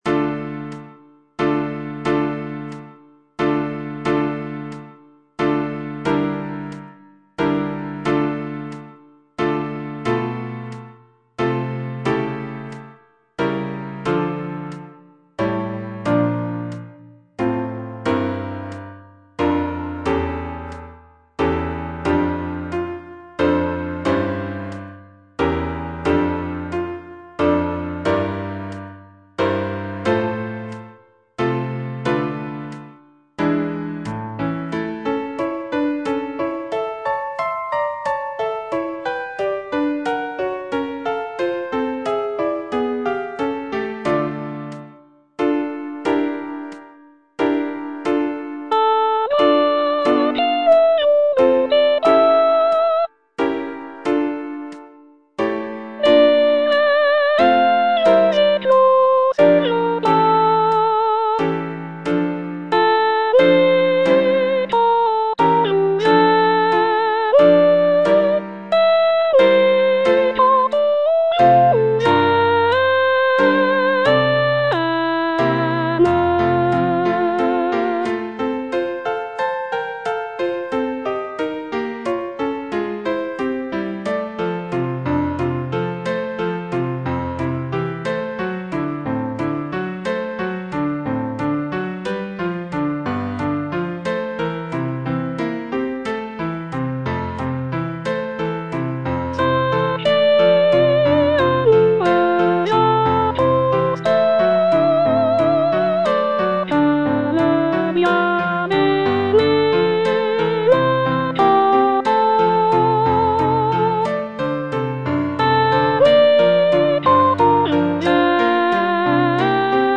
G. FAURÉ - MADRIGAL Soprano (Voice with metronome) Ads stop: auto-stop
It is a setting of a French poem by Armand Silvestre, featuring lush harmonies and intricate counterpoint typical of Fauré's style. The piece is written for four-part mixed choir and piano accompaniment, and showcases Fauré's skill in crafting elegant, lyrical melodies.